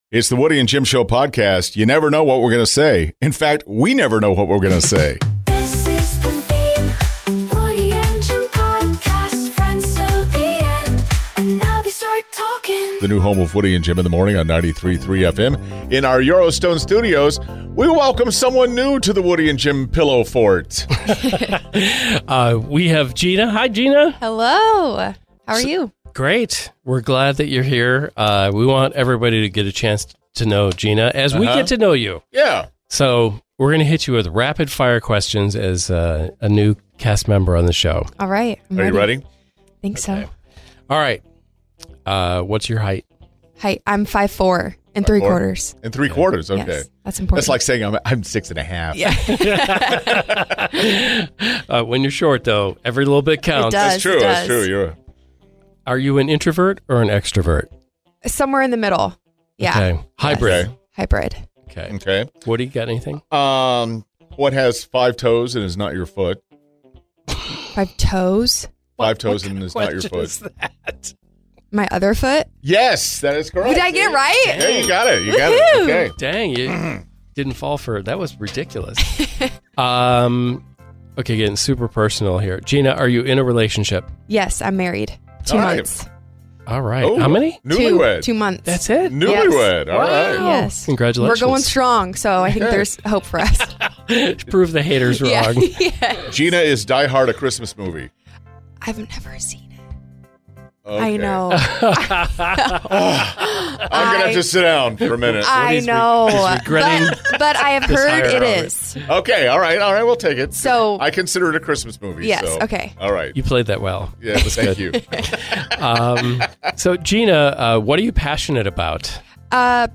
Audible Gasps